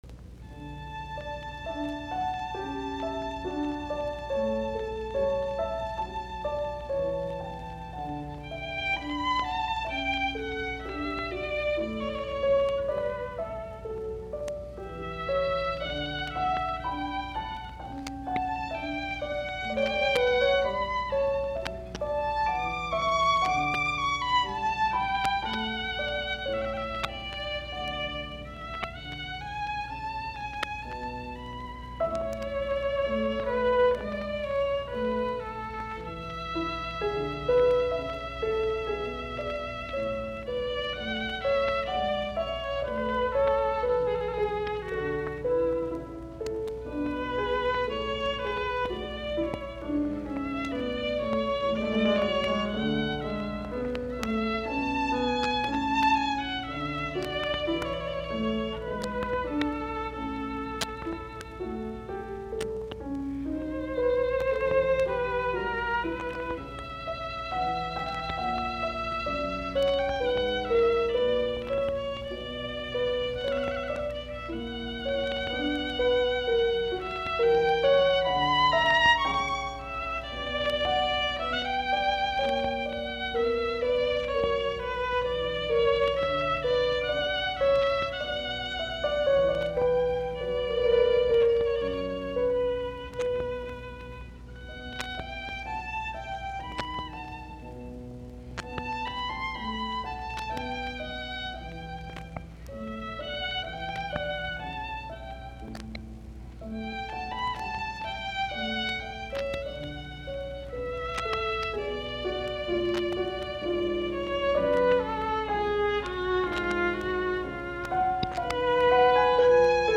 sov. viulu, piano
Soitinnus: Viulu, piano.